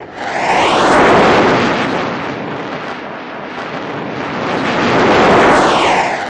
F-104 Starfighter
F-104 End, Rev Loop